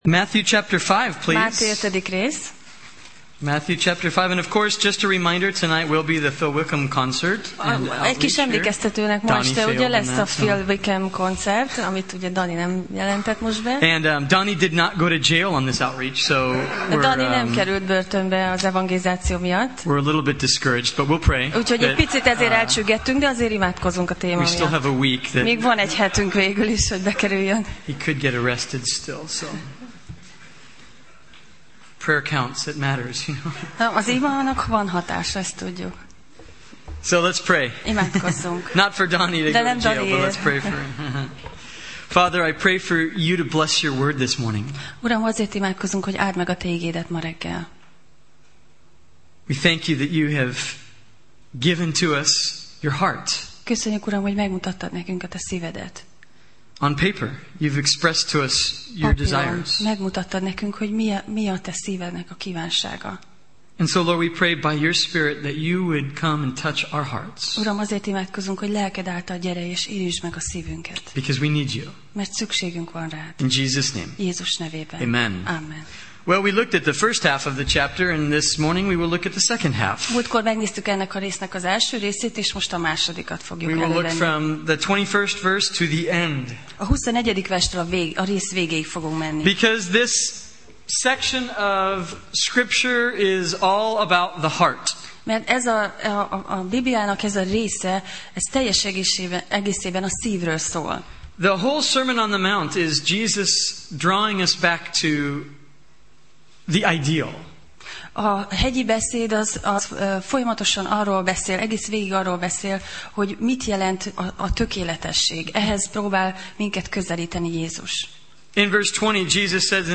Máté Passage: Máté (Matthew) 5:21–48 Alkalom: Vasárnap Reggel